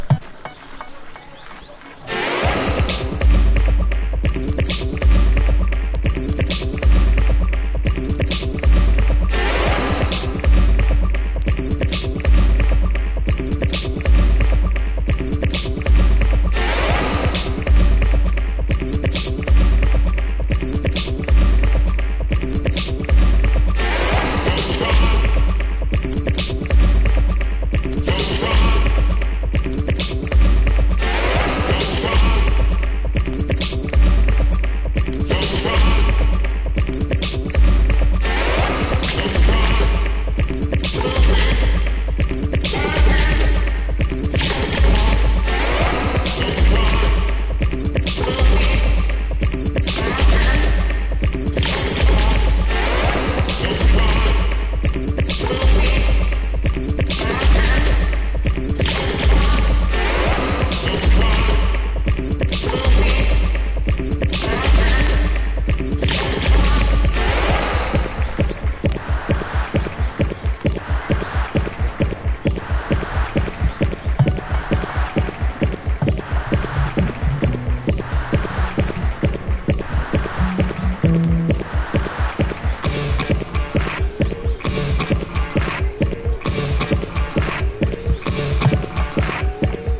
チルウェイヴ以降の新感覚ダヴ・アルバム!!